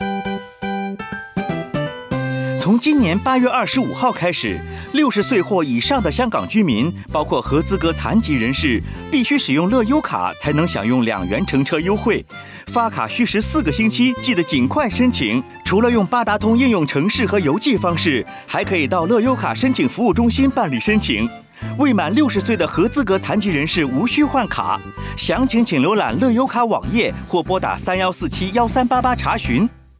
電台廣播